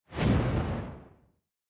fissure-explosion-3.ogg